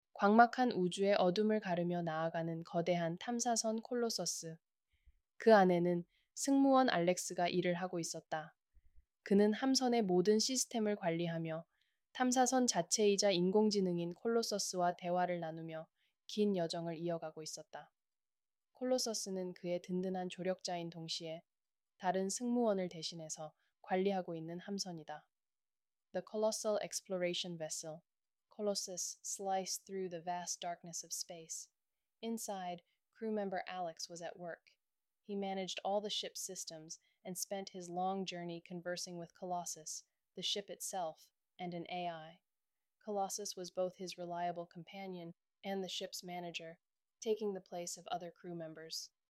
Voice Design(보이스 디자인)이라는 기능을 출시했습니다. 이 기능은 프롬프트만으로 목소리를 생성해주는 서비스입니다.
이렇게 생성한 목소리 몇 가지를 들어보겠습니다.